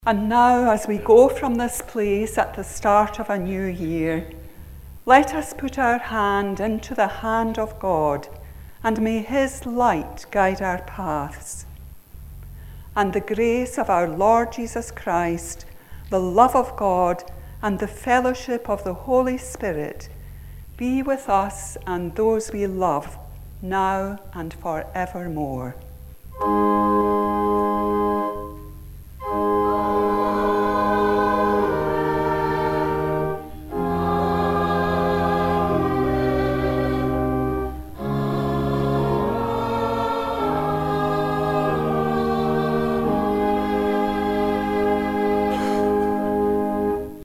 Benediction.